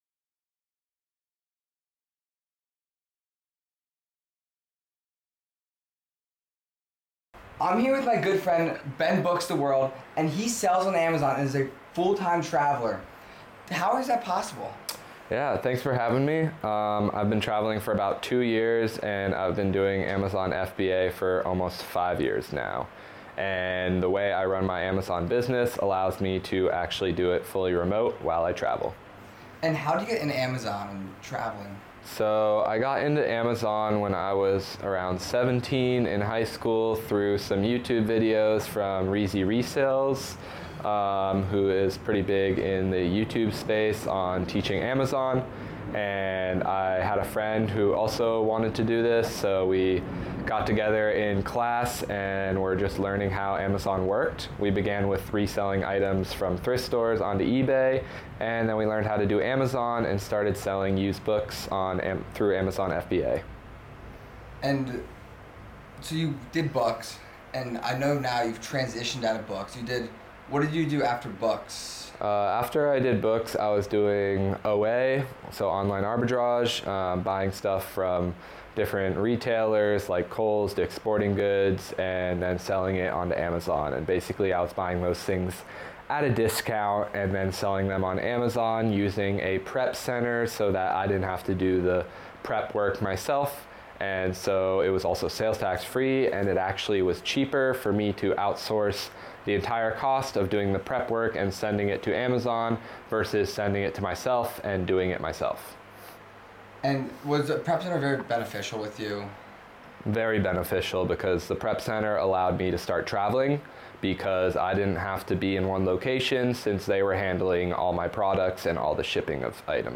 A Lifestyle Discussion